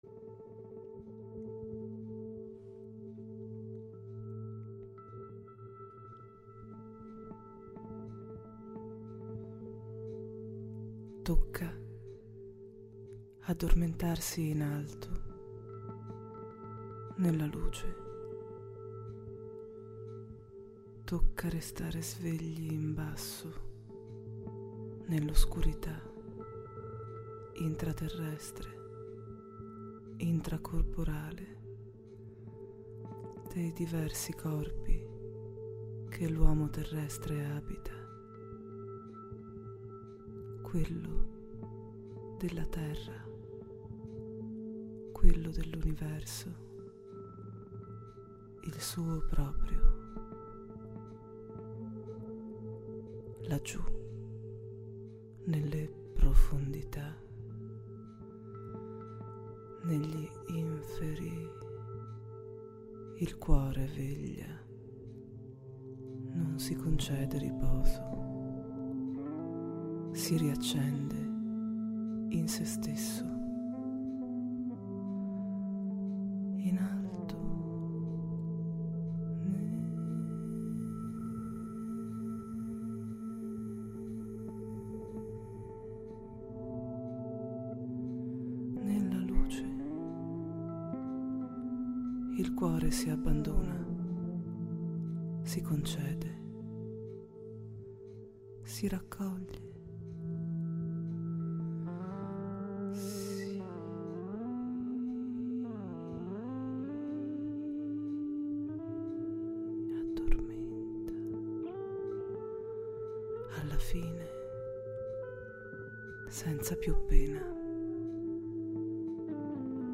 Female
Confident, Corporate, Deep, Natural, Posh, Reassuring, Warm, Versatile
North Italy (native)
Microphone: RODE NT1